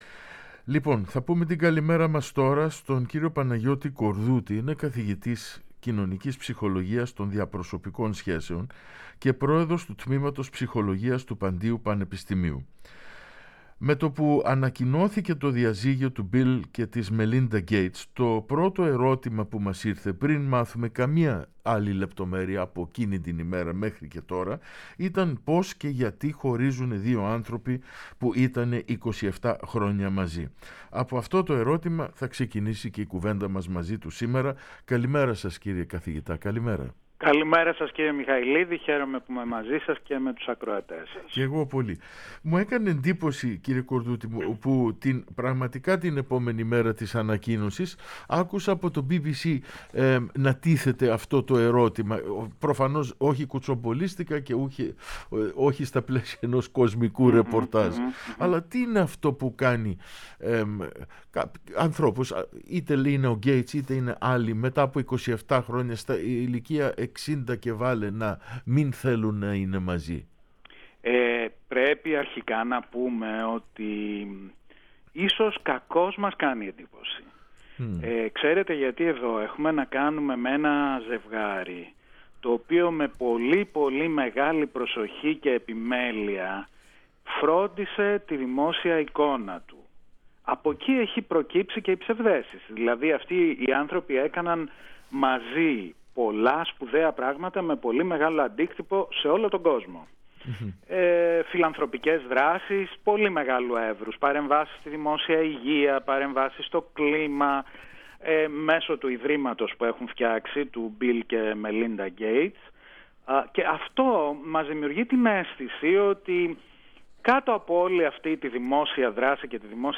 μιλώντας στο Πρώτο Πρόγραμμα και στην εκπομπή «Καθρέφτης»